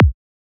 edm-kick-06.wav